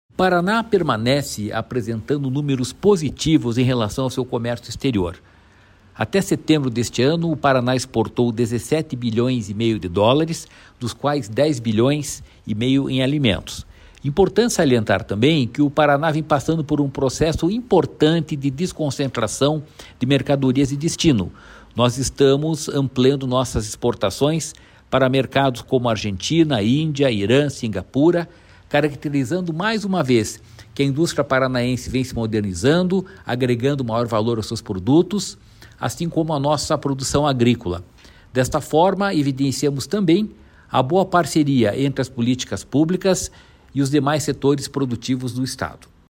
Sonora do presidente do Ipardes, Jorge Callado, sobre o Paraná ser o 5º maior exportador do Brasil | Governo do Estado do Paraná